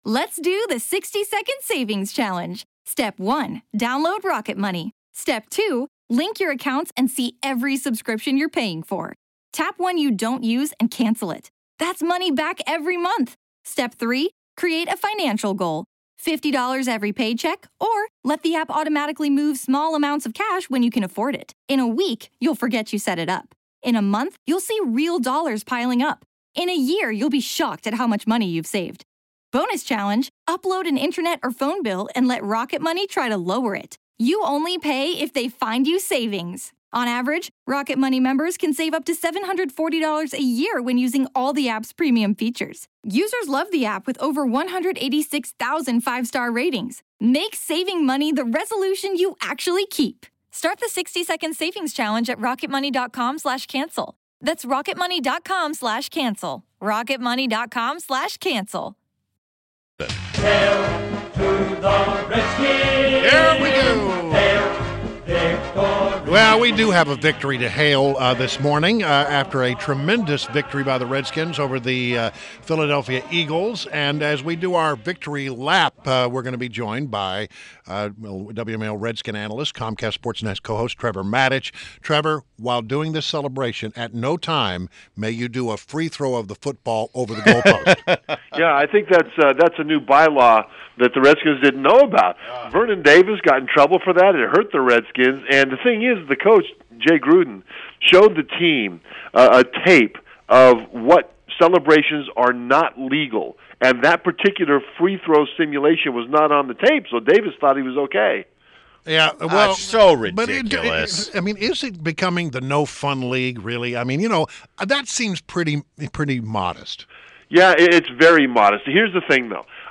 INTERVIEW — TREVOR MATICH — Redskins elite long snapper, WMAL’s Redskins analyst and Comcast SportsNet co-host